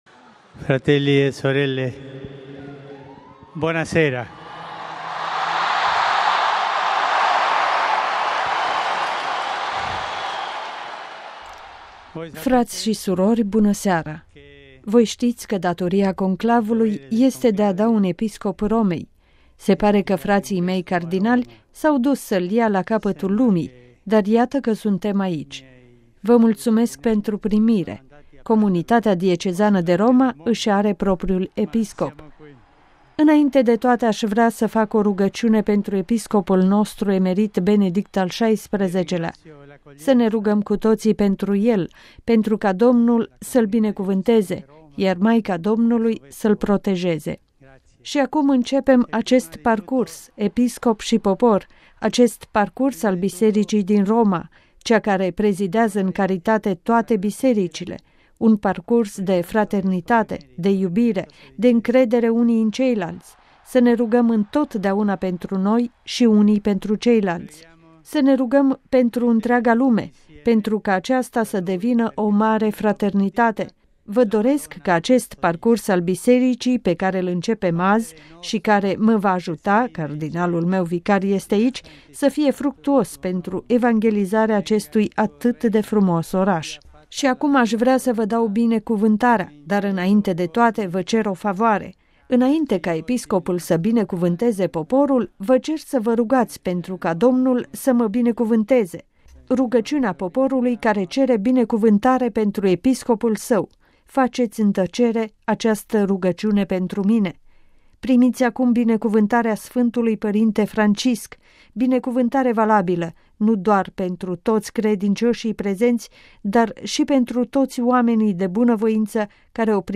Primele cuvinte adresate de Episcopul Romei, Papa Francisc, de la balconul binecuvântărilor